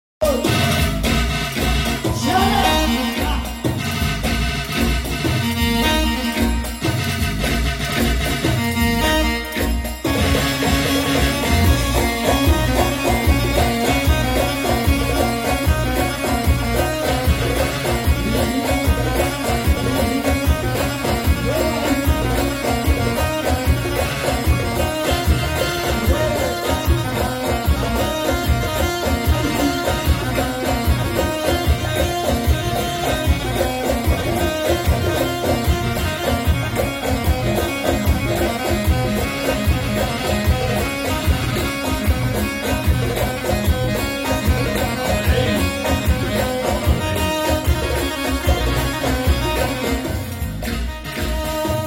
ايقاع الربع عراقي